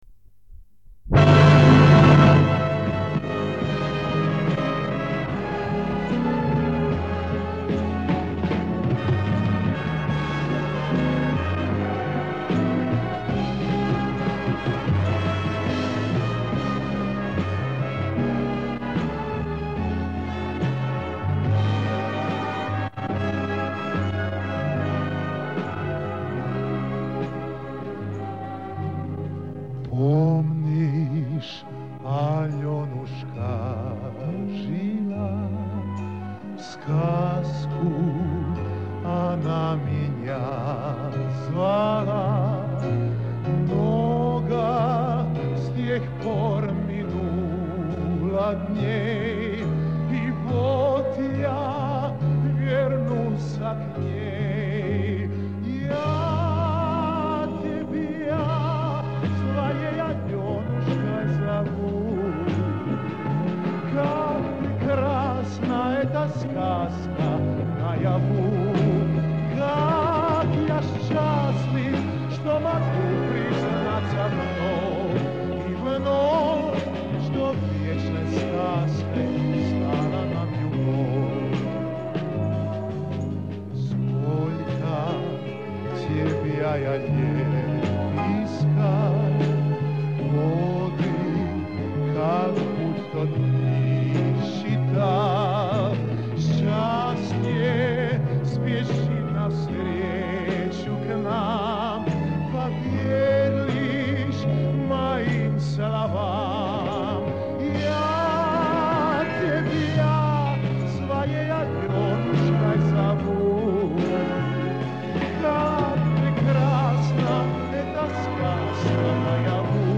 Пели с акцентом (певец явно не русский)